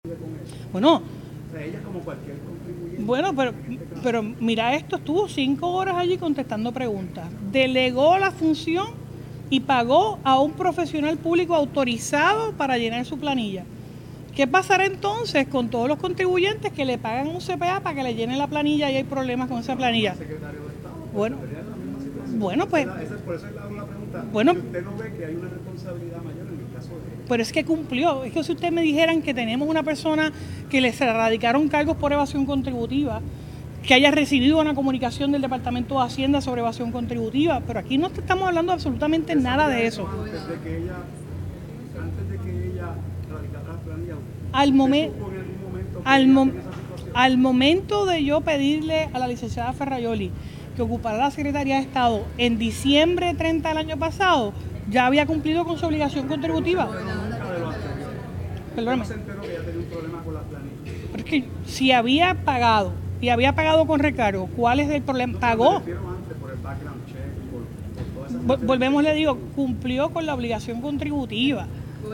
En conferencia de prensa, la primera mandataria destacó la gesta de Ferraiuoli al enfrentar la vista de unas cinco horas, y señaló cómo el pasado 30 de diciembre de 2024, cuando nominó a la secretaria, todo estaba en orden, por lo que indicó no entender por qué condenan que haya solicitado una prórroga.
419-JENNIFFER-GONZALEZ-GOBERNADORA-DEFIENDE-QUE-VERONICA-FERRAIUOLI-CUMPLIO-CON-LA-OBLIGACION-CONTRIBUTIVA.mp3